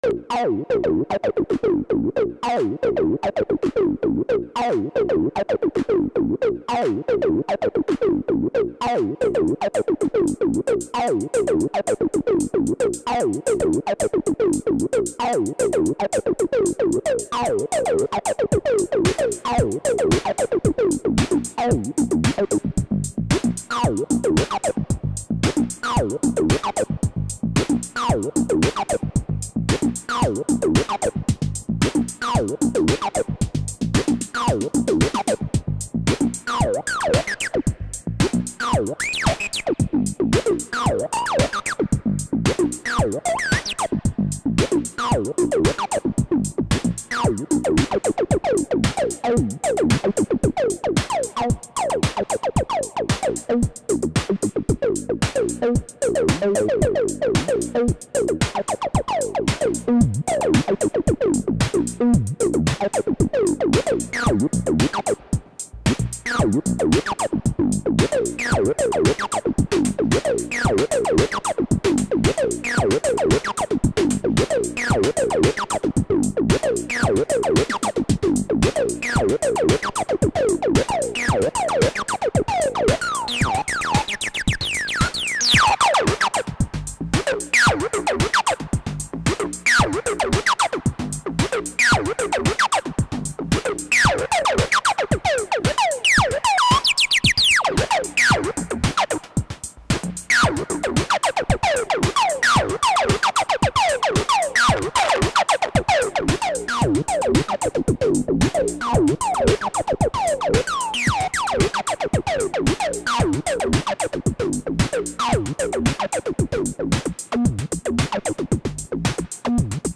Freestyle synth acid